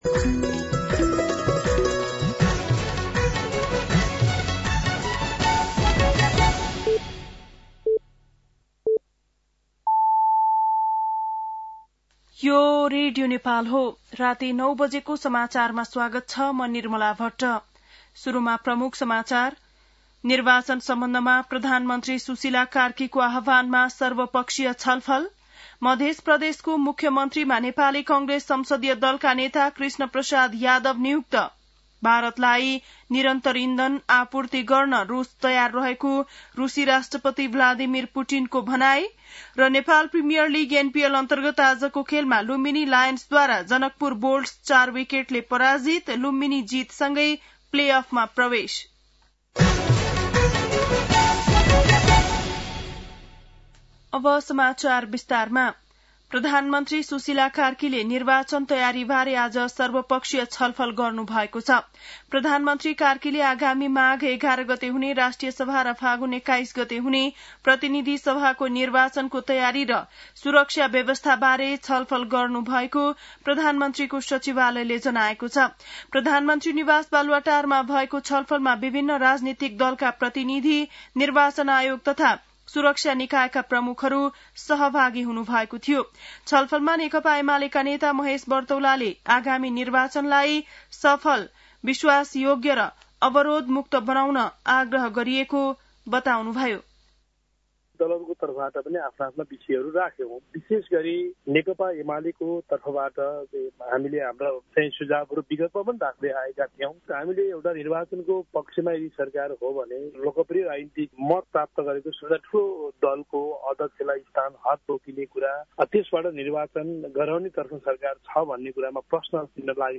बेलुकी ९ बजेको नेपाली समाचार : १९ मंसिर , २०८२
9-pm-nepali-news-8-19.mp3